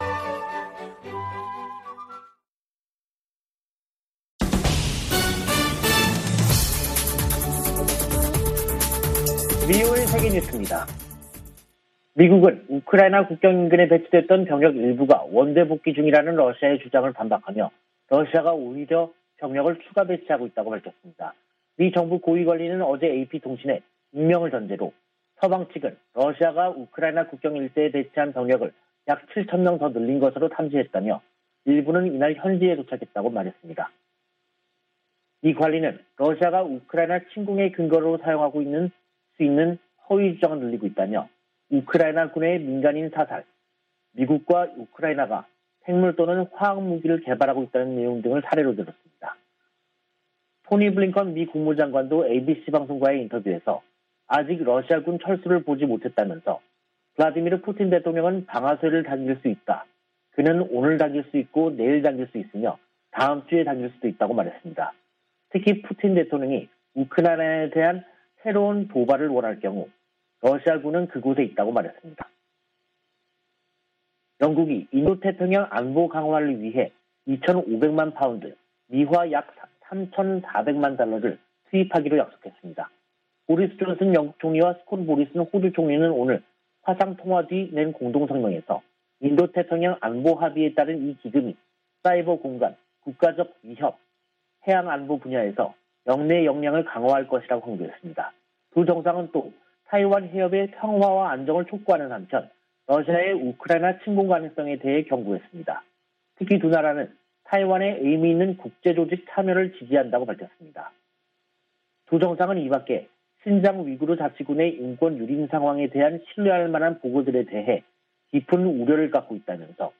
VOA 한국어 간판 뉴스 프로그램 '뉴스 투데이', 2022년 2월 17일 2부 방송입니다. 미국이 핵탄두 탑재 가능한 B-52H 전략폭격기 4대를 괌에 배치했습니다. 미국의 전문가들은 필요하다면 한국이 우크라이나 사태 관련 미국 주도 국제 대응에 동참해야한다는 견해를 제시하고 있습니다. 북한이 한 달 새 가장 많은 미사일 도발을 벌였지만 미국인들의 관심은 낮은 것으로 나타났습니다.